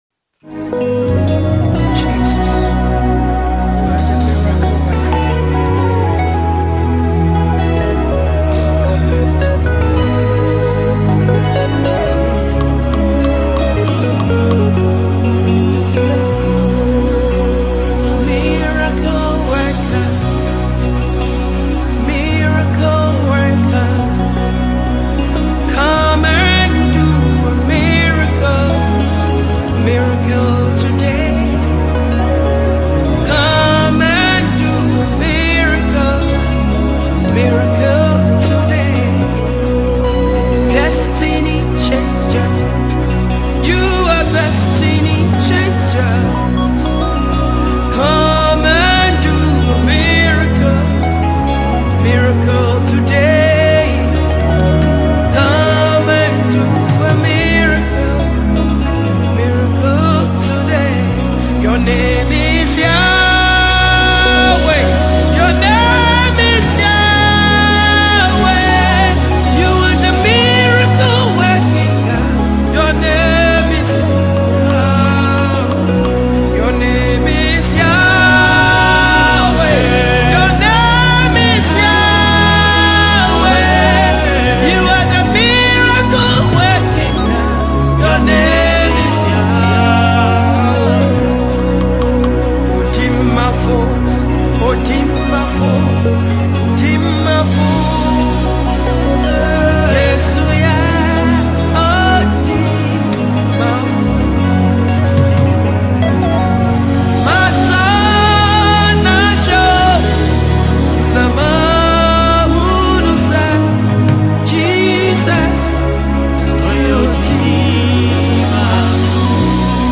This medley is not just entertaining; it’s also uplifting.
Genre: Gospel